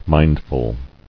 [mind·ful]